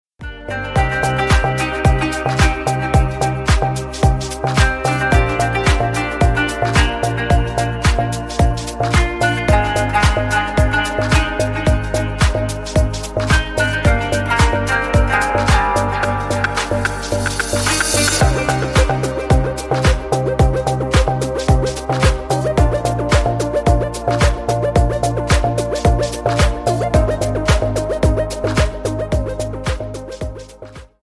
Спокойные И Тихие Рингтоны » # Рингтоны Без Слов
Танцевальные Рингтоны